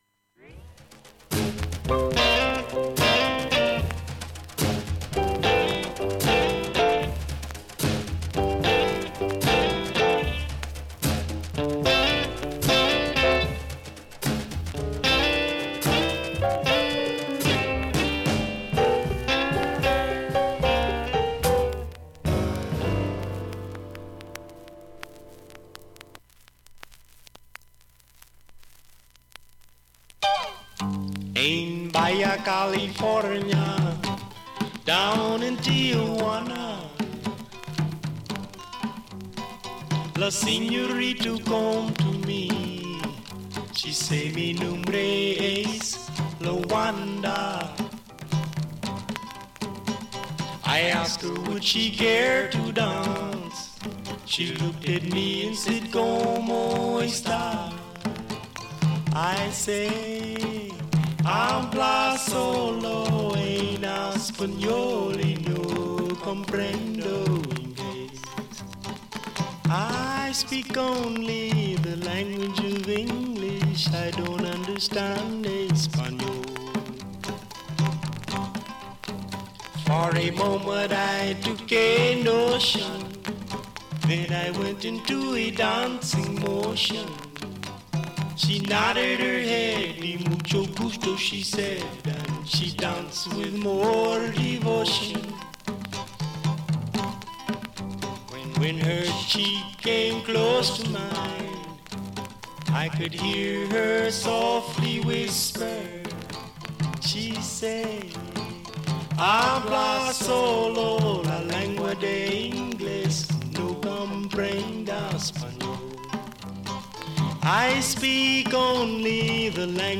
3回までのかすかなプツが5箇所
単発のかすかなプツが９箇所
◆ＵＳＡ盤オリジナル Mono